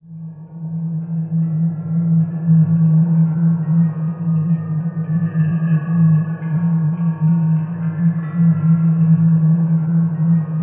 ATMOPAD22 -LR.wav